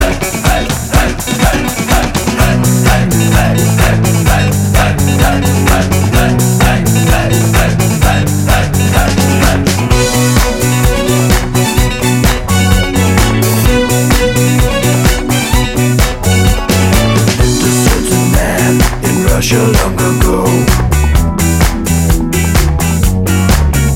Female Solo Version Disco 4:41 Buy £1.50